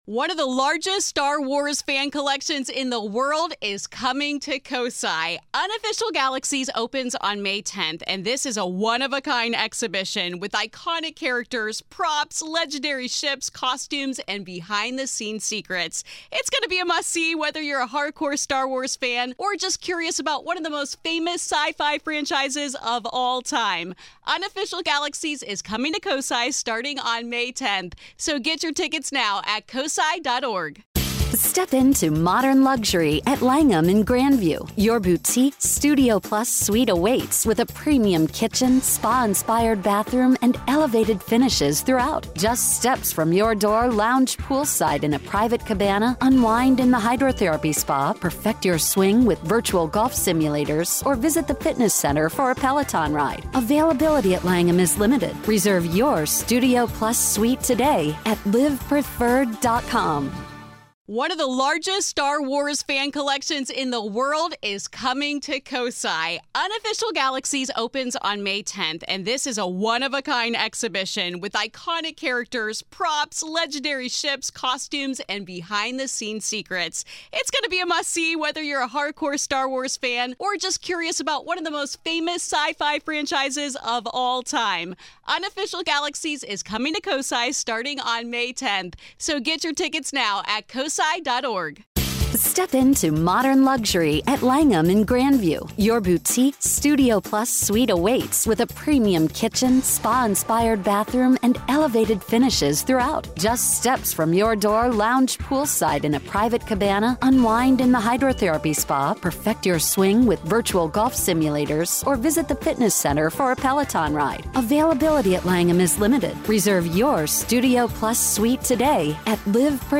The Grave Talks | Haunted, Paranormal & Supernatural / On Location